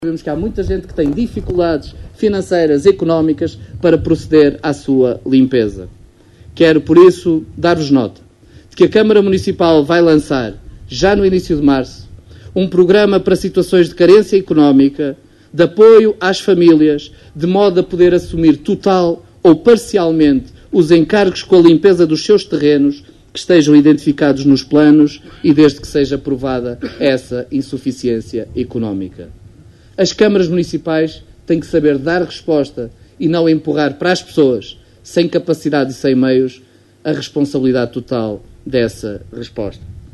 A visita, que contou também com a presença do Ministro da Administração Interna, Eduardo Cabrita, iniciou-se na freguesia de Ancora, seguindo depois para Riba de Âncora onde teve lugar a cerimónia de assinatura do protocolo para a contratação e funcionamento das equipas de intervenção permanente que irão ficar sediadas nas corporações de bombeiros de Caminha e Vila Praia de Âncora.
Miguel Alves, presidente da Câmara de Caminha, foi o primeiro a usar da palavra para deixar a garantia de que a Câmara se irá substituir aos particulares na limpeza dos terrenos, sempre que se comprove carência pessoal ou económica.